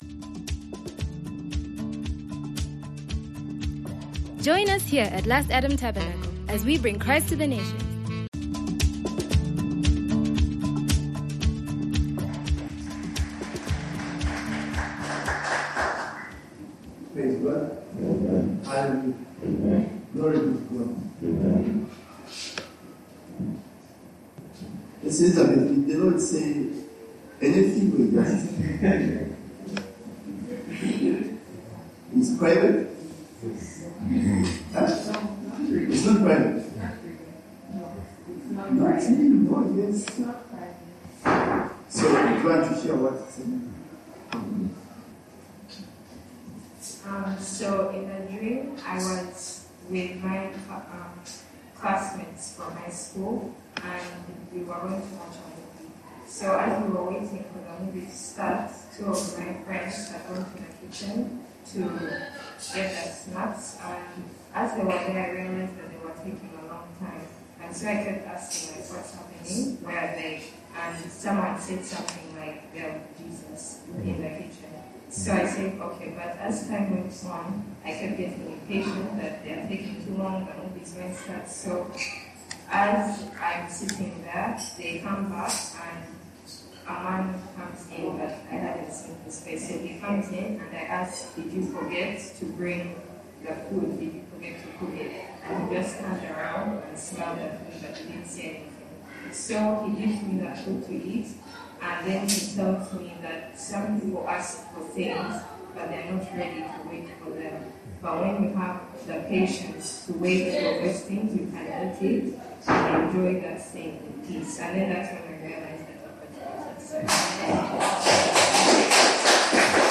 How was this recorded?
Note: Loss in quality of audio recording will be experienced.